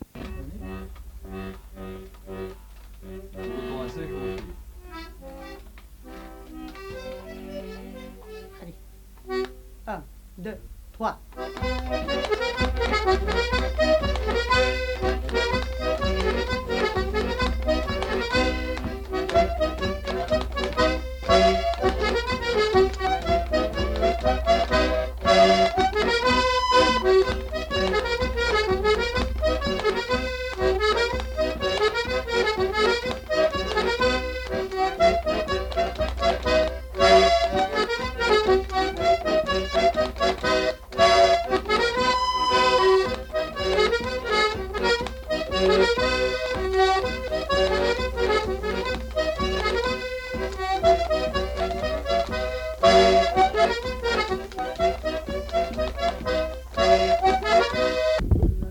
Aire culturelle : Savès
Lieu : Polastron
Genre : morceau instrumental
Instrument de musique : accordéon diatonique
Danse : rondeau